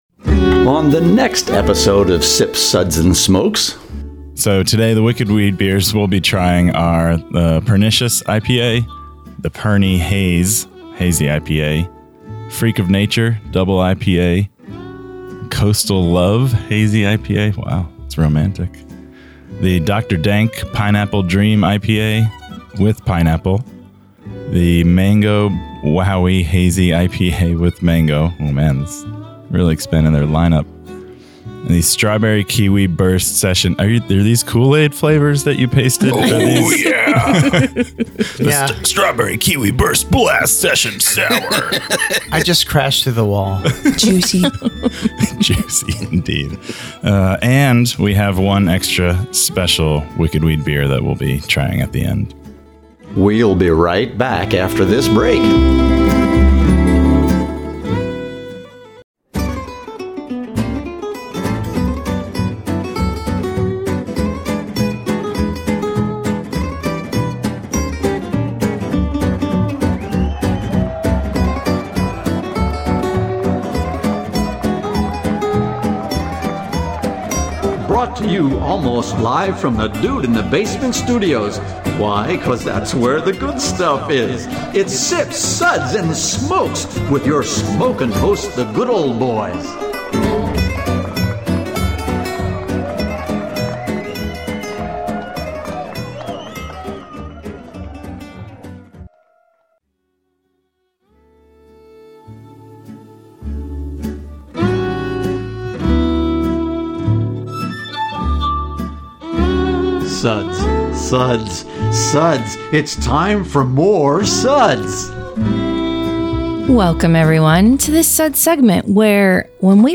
Radio MP3 with Music Beds
Location Recorded: Nashville,TN